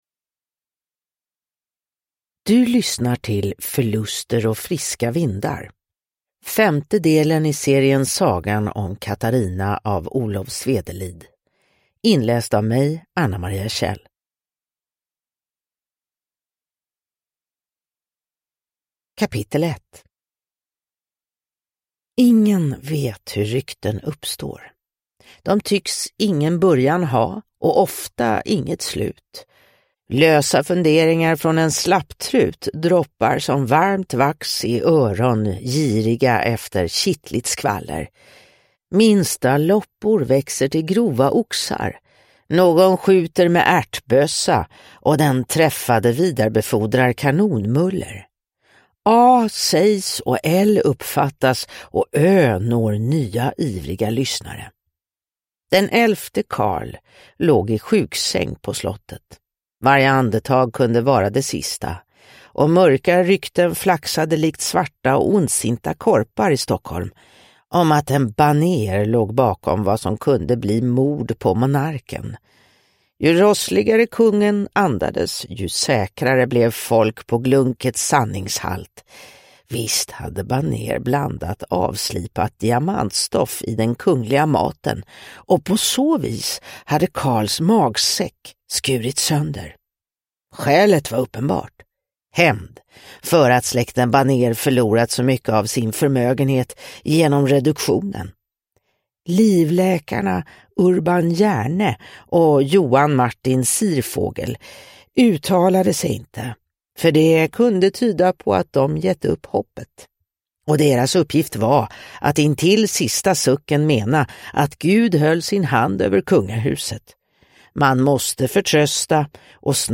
Förluster och friska vindar – Ljudbok – Laddas ner